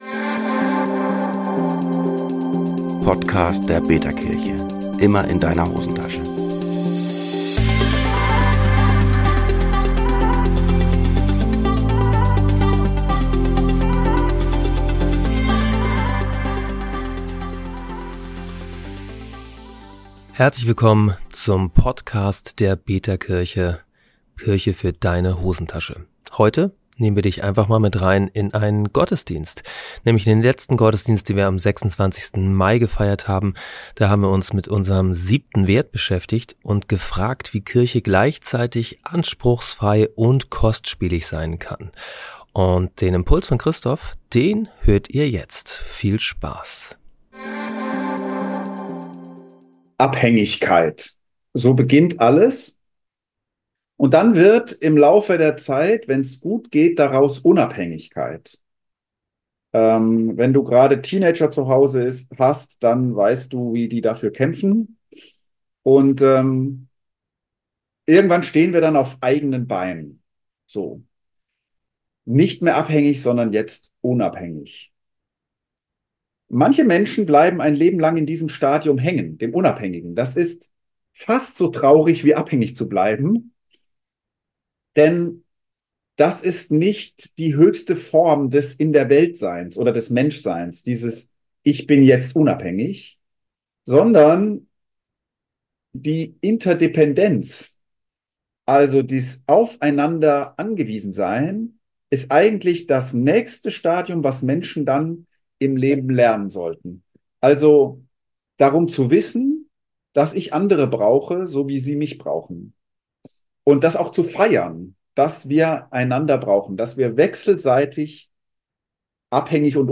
Du hast dich immer schon gefragt, wie ein Gottesdienst in der betaKirche aussehen kann? Am 26. Mai haben wir uns mit unserem 7. Wert beschäftigt und uns gefragt, wie Kirche gleichzeitig anspruchsfrei und kostspielig sein kann.